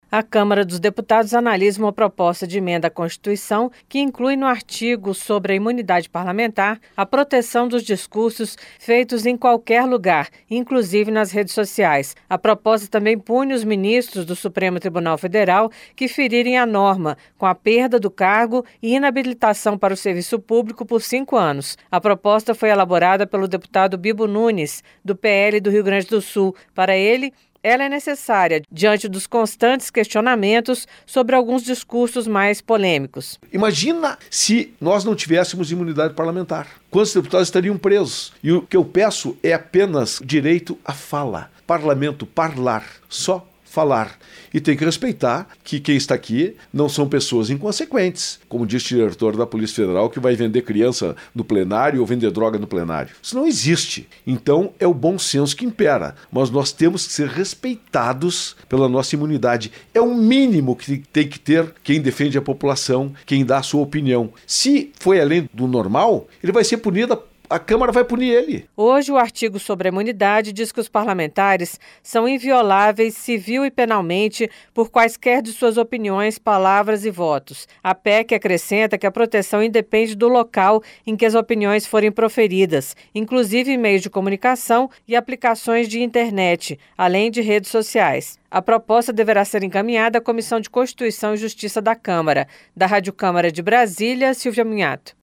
Portal da Câmara dos Deputados